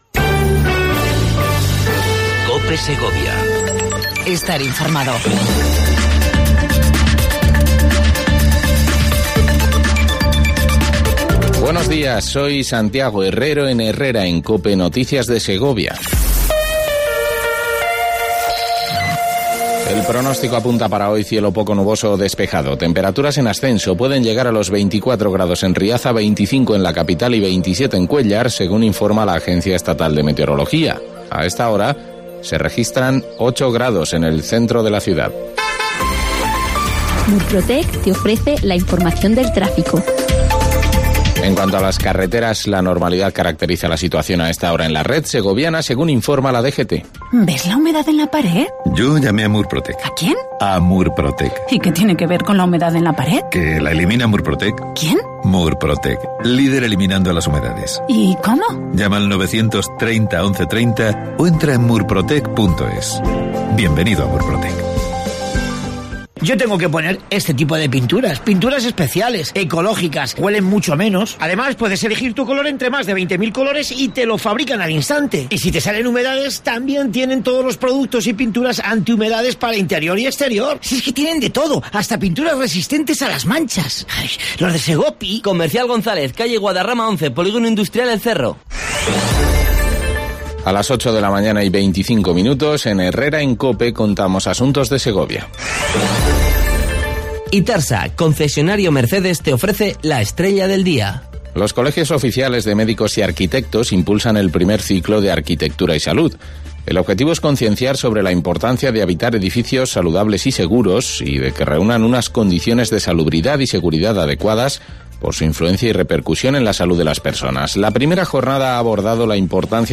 AUDIO: Segundo informativo local en cope segovia 03/10/18